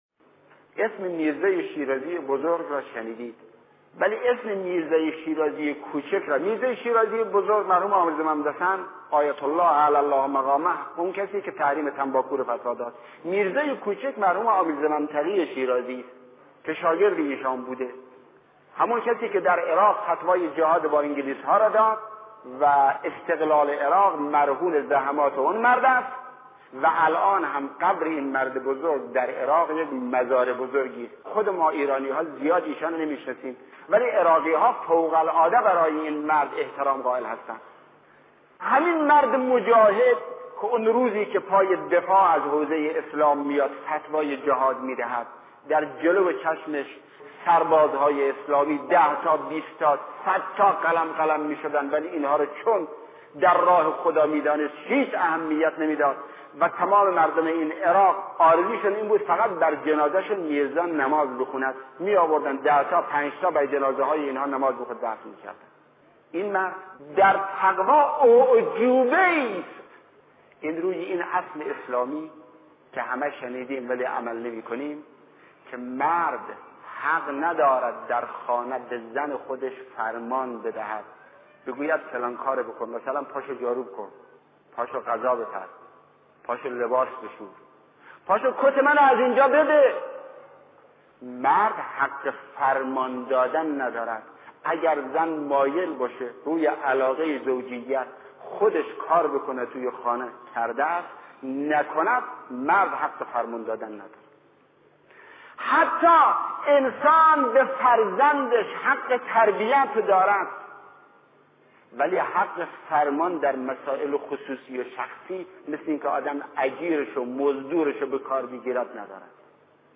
گروه چندرسانه‌ای ــ آیت‌الله شهید مرتضی مطهری در یکی از سخنرانی‌های خود، ضمن بیان داستانی شنیدنی از آیت‌الله شیخ محمدتقی شیرازی به بیان سخنانی در مورد حق نداشتن مردان برای فرمان دادن در منزل پرداخته است.